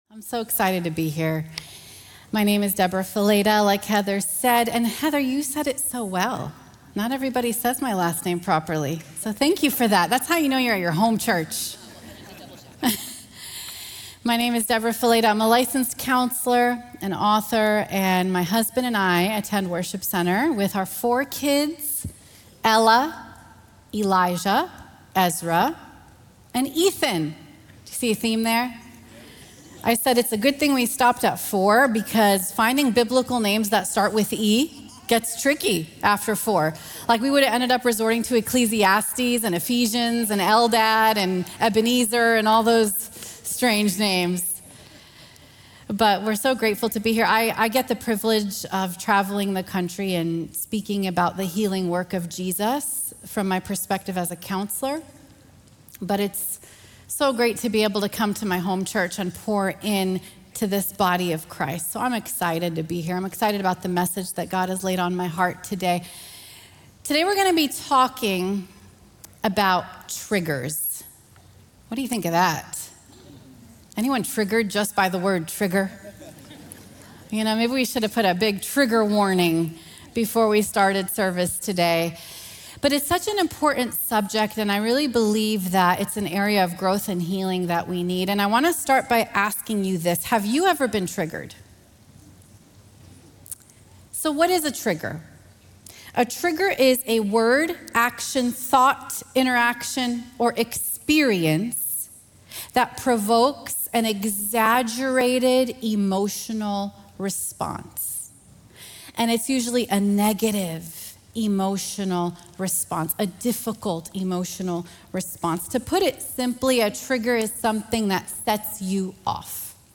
Tune in for a powerful message from Licensed Professional Counselor